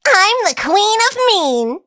willow_kill_vo_06.ogg